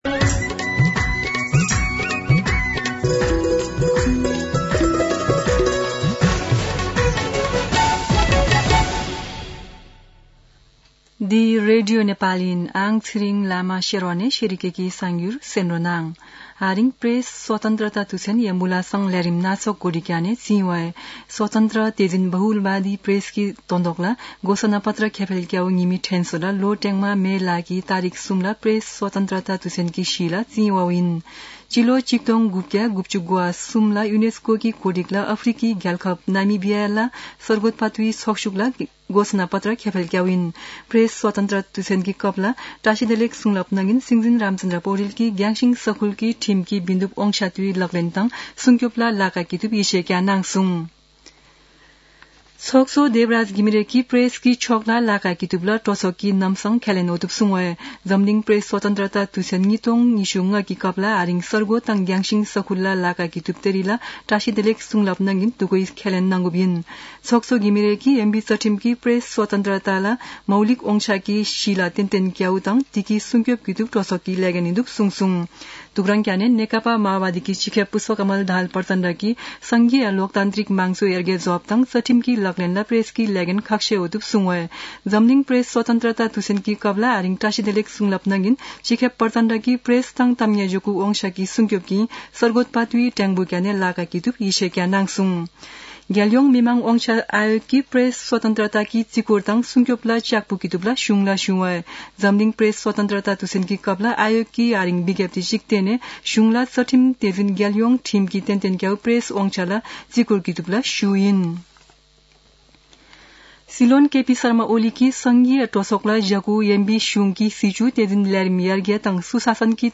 शेर्पा भाषाको समाचार : २० वैशाख , २०८२
Sherpa-News-20.mp3